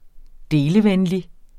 Udtale [ ˈdeːləˌvεnli ]